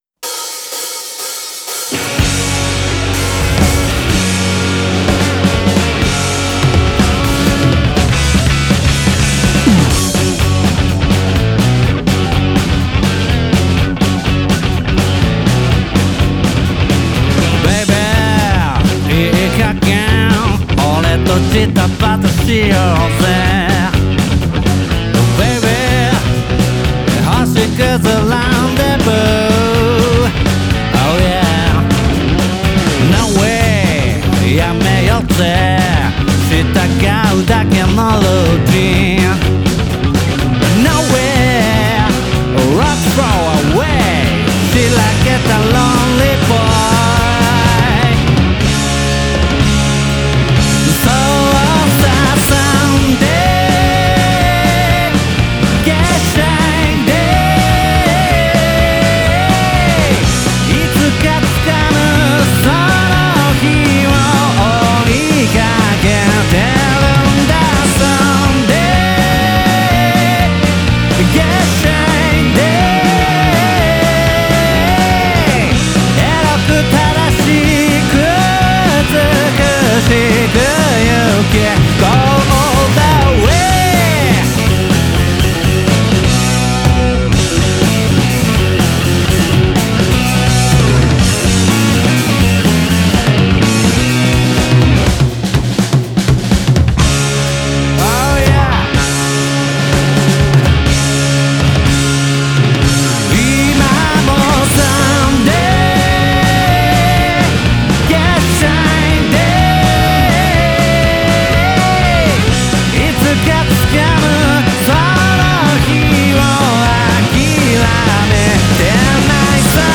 "ロック・バンドのサポート・ドラマー"をイメージして叩きまくろう！
デモ・ドラム入り
ブルースを基盤とした黒いグルーヴに焦点を絞り、独自のロックンロールを鳴らしている。